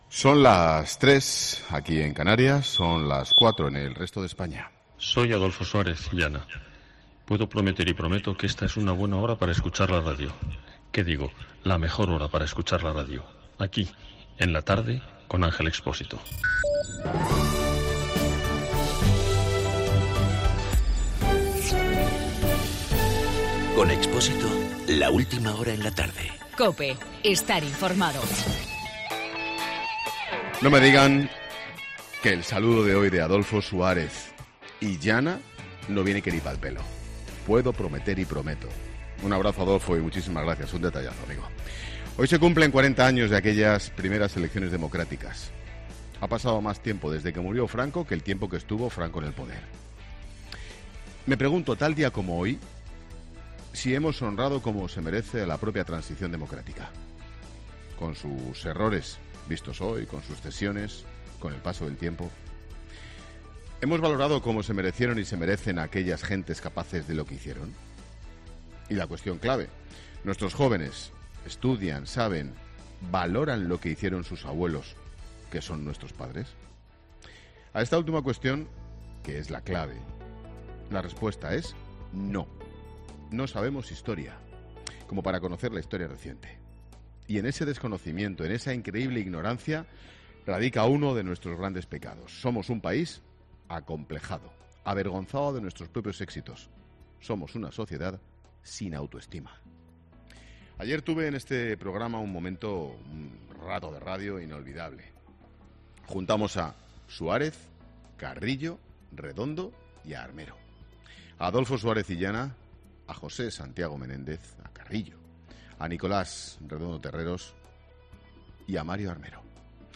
Monólogo de Expósito
Monólogo de Ángel Expósito a las 16h, desde Santa Cruz de Tenerife, sobre los 40 años de las primeras elecciones democráticas.